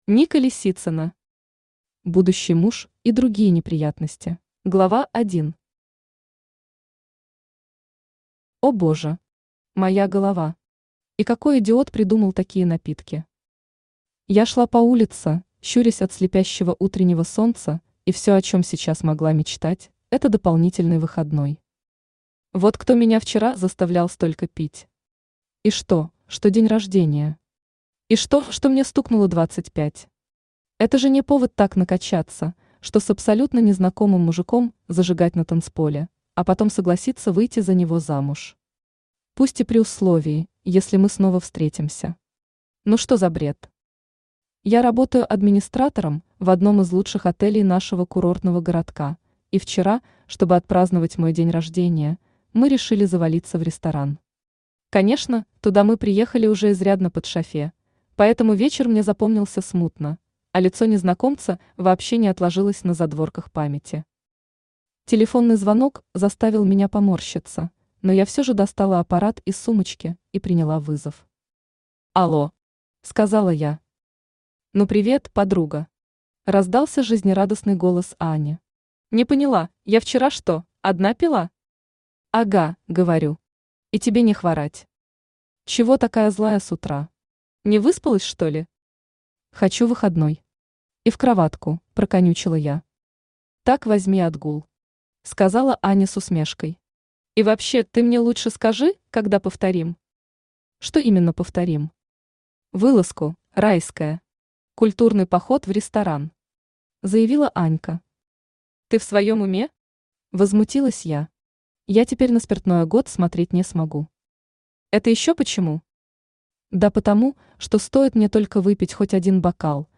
Аудиокнига Будущий муж и другие неприятности!
Автор Ника Лисицына Читает аудиокнигу Авточтец ЛитРес.